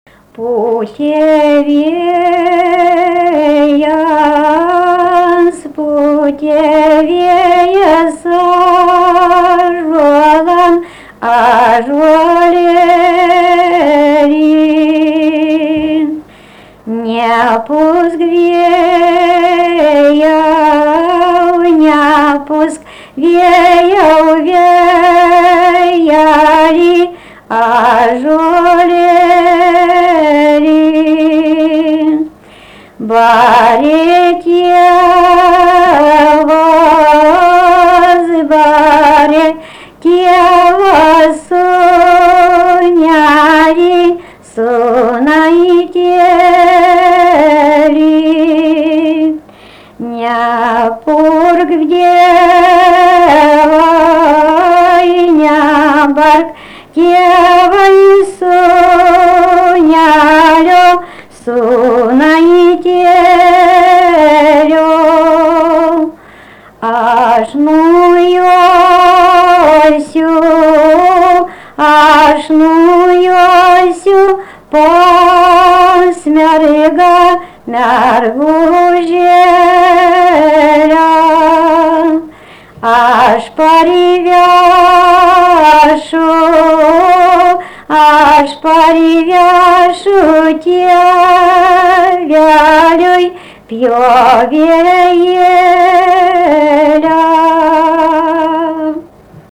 Subject daina
Erdvinė aprėptis Gudžionys
Atlikimo pubūdis vokalinis